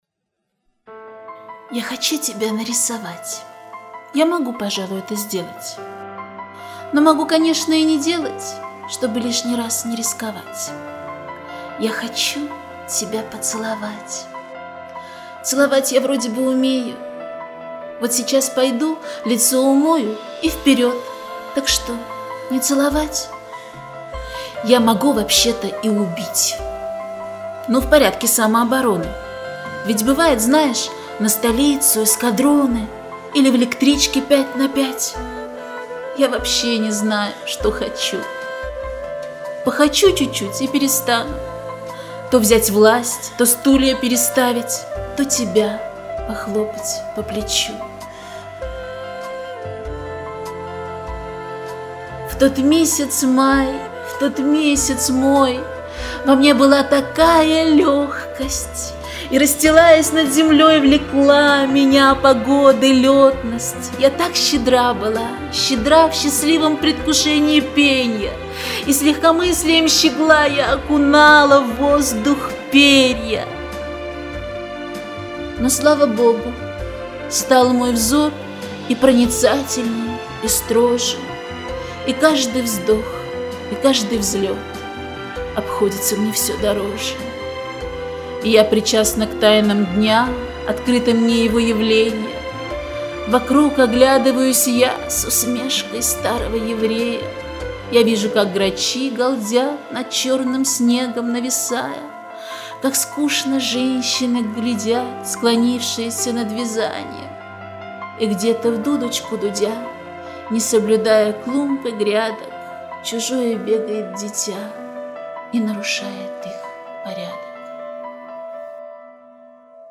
«Декламация»
прекрасный звук, легко слушаешься по времени (2 минуты)